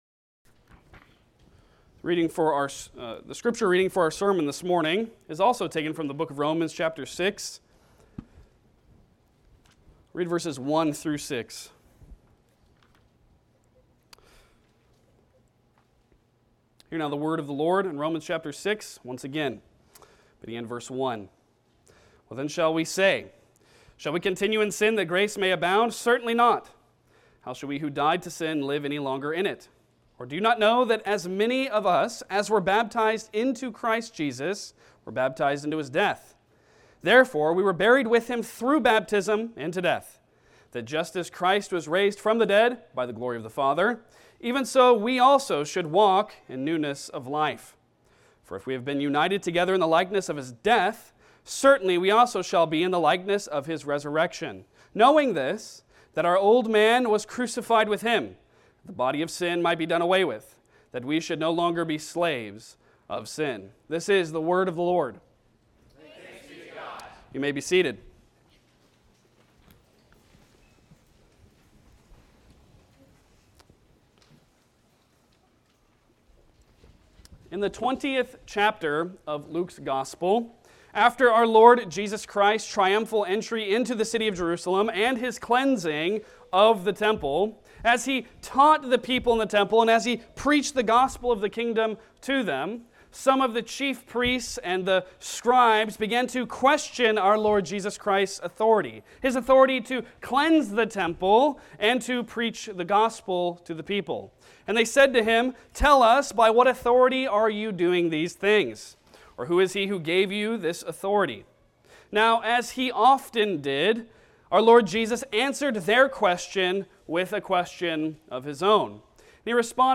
Romans 6:3-4 Service Type: Sunday Sermon Download Files Bulletin Topics